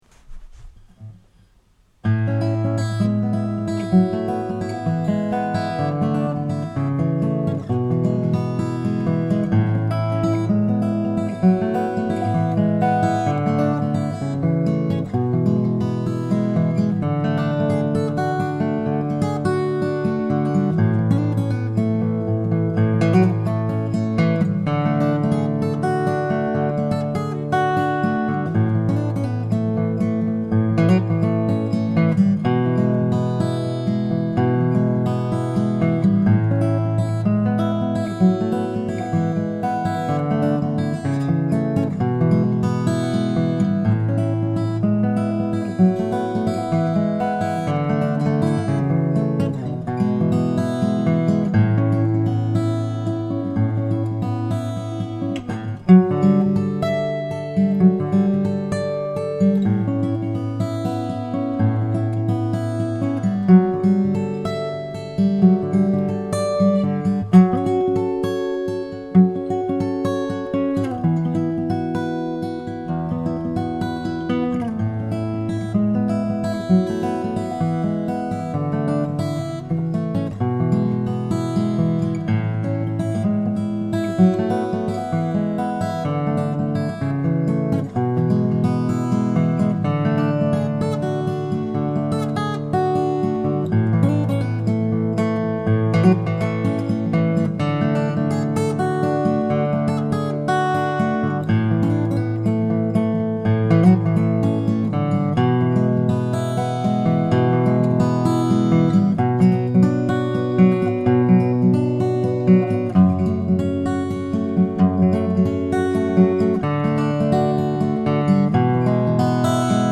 my wife says that this sketch isn’t “peppy.” i think it bounces along well enough. it feels like a throwback to some folksy fireside geetar music mixed up with an etude by fernando sor. i think it’s light and has a lot of space.
my rationale for this is pretty simple. when i was studying the classical guitar full time, i learned that if you stop when you make a mistake, you never really get past it. you learn to get to the point of the mistake, make the mistake and stop. so i play through it. this is intimidating at first since it’s going directly to disk for me to hear again and again, but so what? i have a delete key. but i don’t use it. for the same reason that i used to compose in pen: i might not like something now, but it could make sense later.
music, original music